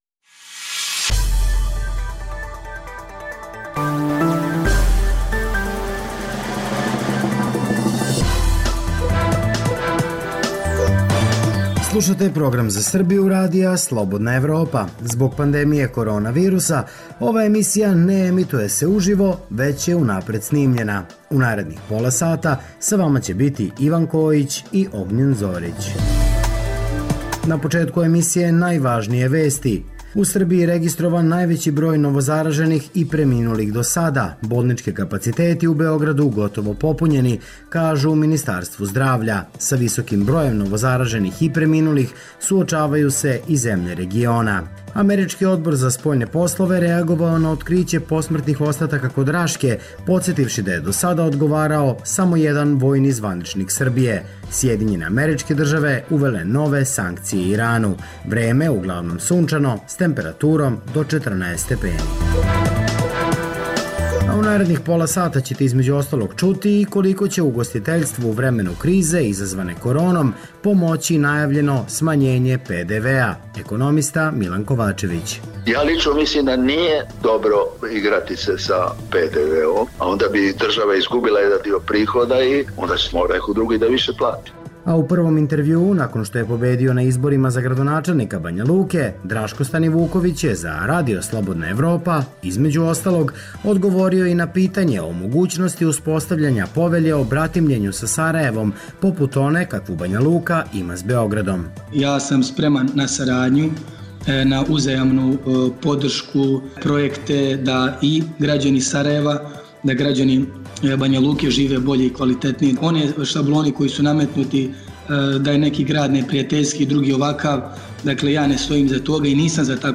Koliko će ugostiteljstvu u vremenu krize izazvane koronom pomoći smanjenje PDV-a? U prvom intervjuu nakon pobede na izborima za gradonačelnika Banjaluke, Draško Stanivuković za RSE između ostalog odgovorio i na pitanje o mogućnosti uspostavljanja Povelje o bratimljenju Banjaluke sa Sarajevom